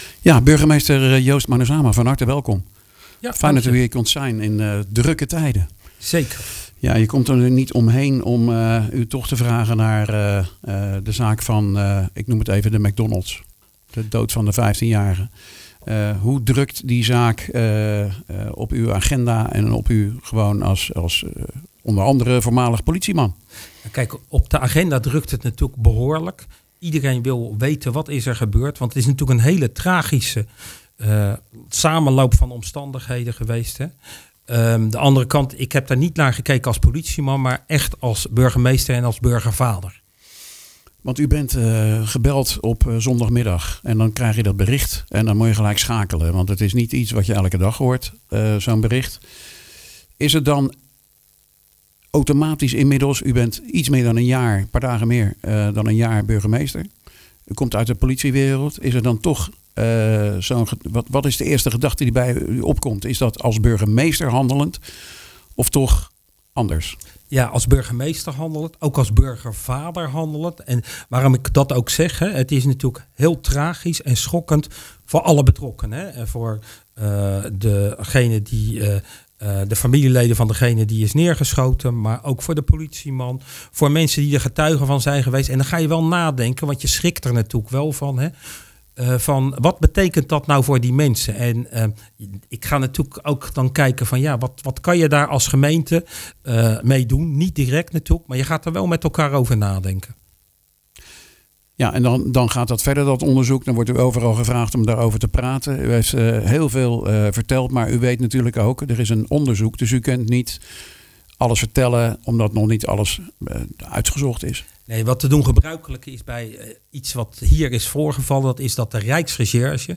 In IJssel-nieuws van 29 september was te gast burgemeester Joost Manusama. Hij vertelde over de zaak van de beroving van een fatbike en aansluitend het doodschieten van een 15-jarige.